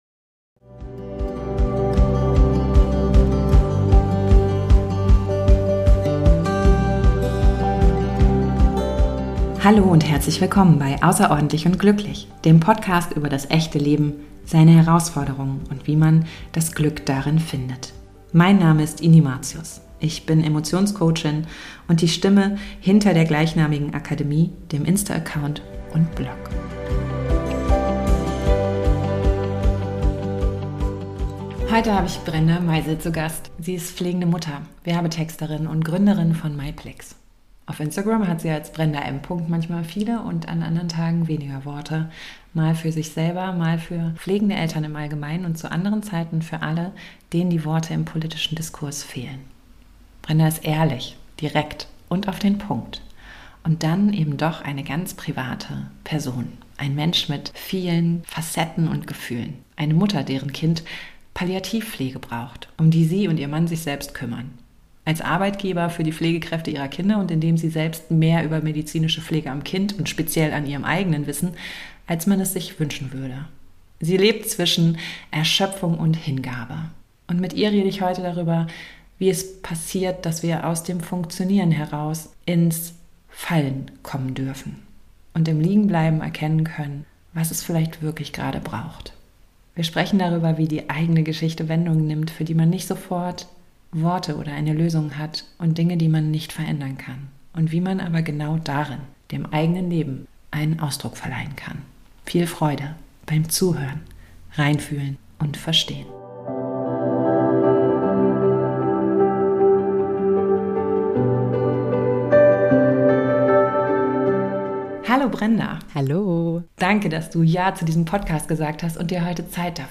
Freut euch auf ein ehrliches, bewegendes und inspirierendes Gespräch.